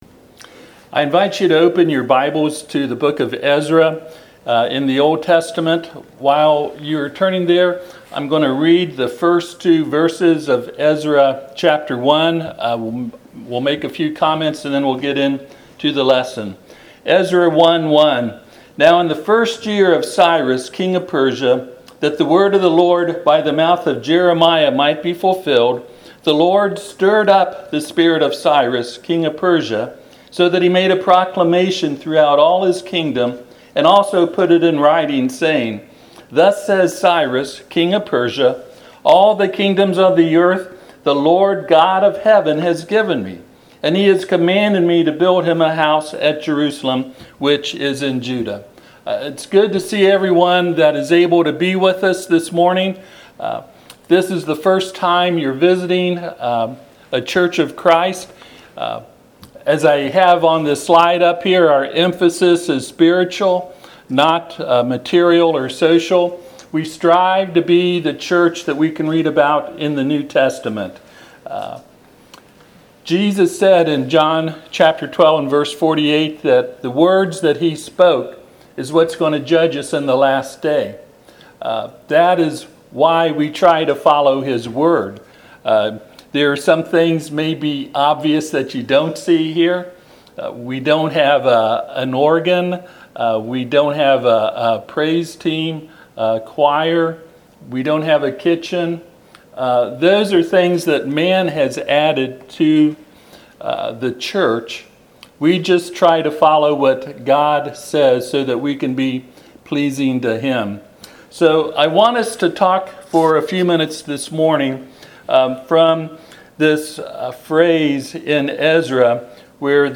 Passage: Ezra 1:1-11 Service Type: Sunday AM